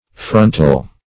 Frontal \Fron"tal\, a. [Cf. F. frontal.]